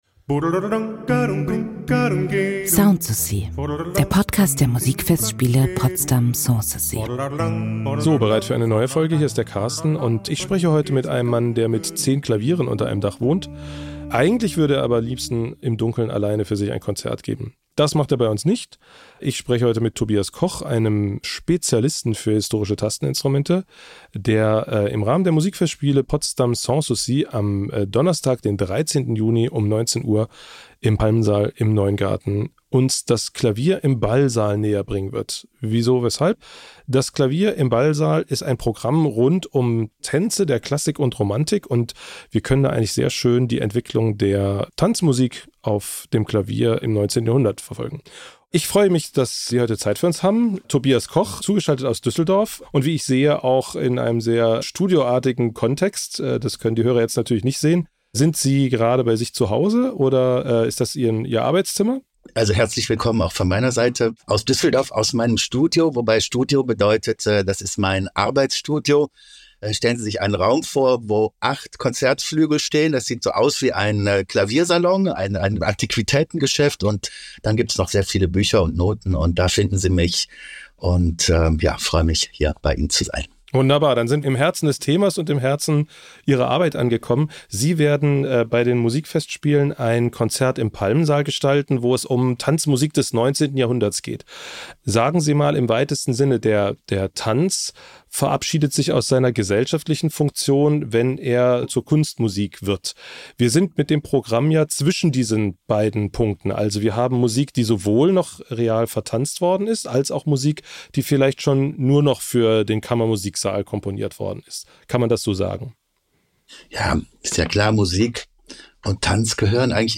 Wir besuchen Schubert, der Ländler zum Heurigen und den geselligen Zusammenkünften seiner Freunde komponiert, erfahren mehr über Nationaltänze wie Mazurka oder Eccossaisen und machen auch Station bei den Charakterstücken Robert Schumanns und den Klavierwalzern Frédéric Chopins. Ein Gespräch im Dreivierteltakt!